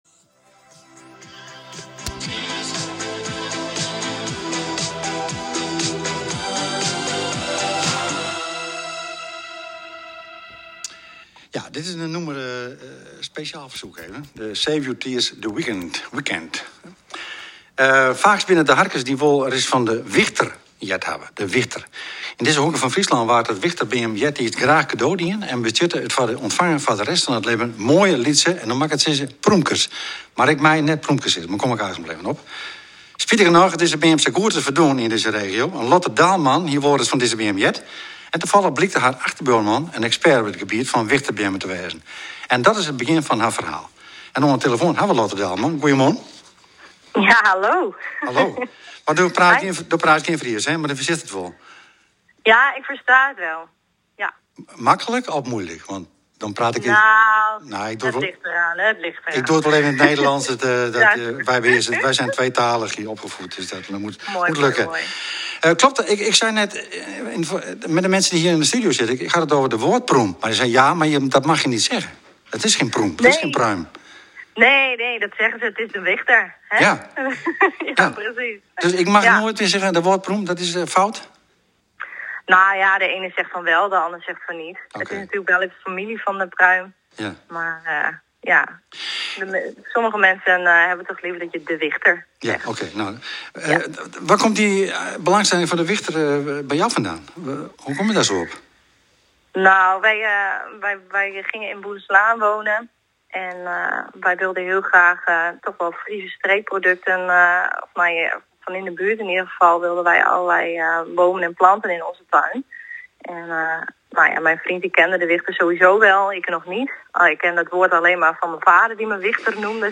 Zaterdag 6 februari 2021 zijn we op de radio geweest bij RTVNOF bij het programma Op’e Hichte!